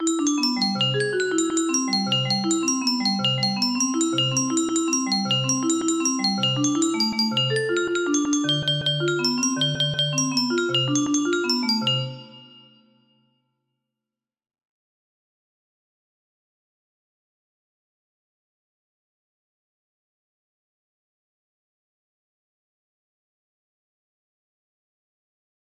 MELODIA CLÁSICA music box melody